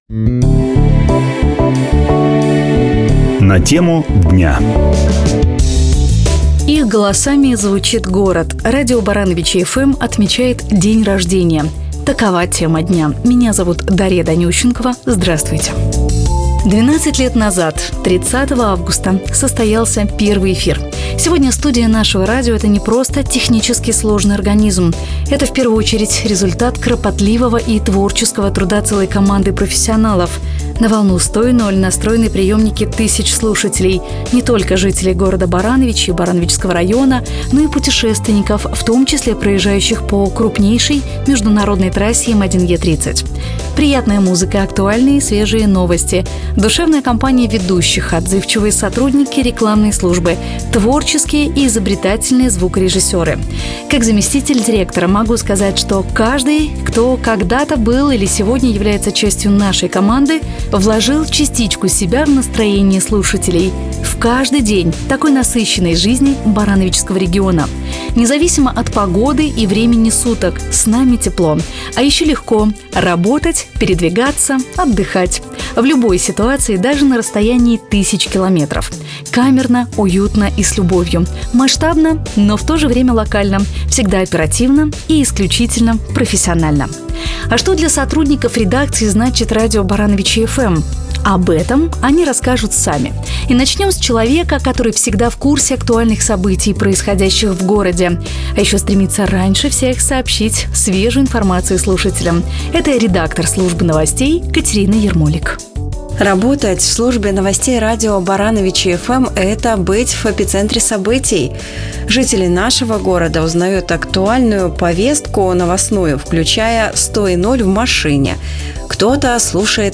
Ведущие в студии обсуждают актуальные темы, проводят конкурсы, заряжают слушателей хорошим настроением.